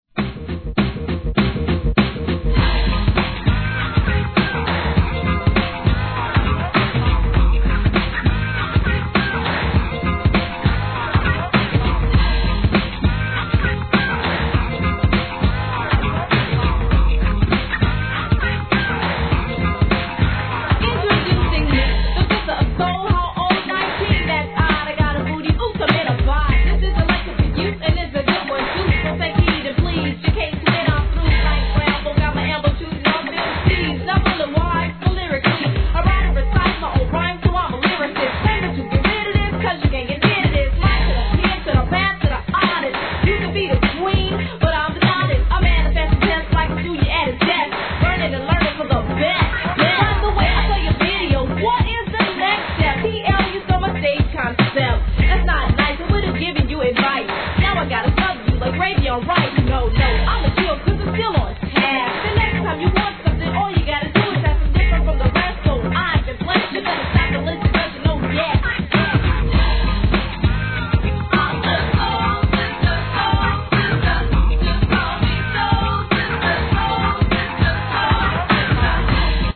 HIP HOP/R&B
NEW JACK SWINGな跳ねBEATで王道なフィメールRAP!!!